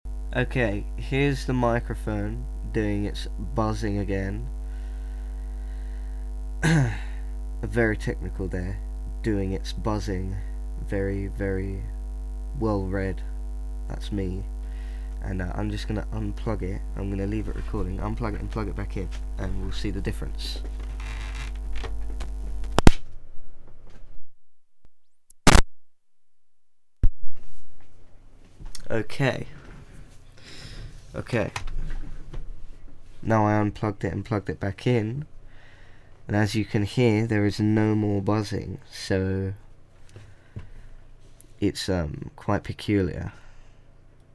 Electrical Buzzing Sound
/uploads/default/original/2X/7/7893aece264fd948a6933c4b753e7e0d5f78d260.mp3 The buzzing is mains hum (50Hz type).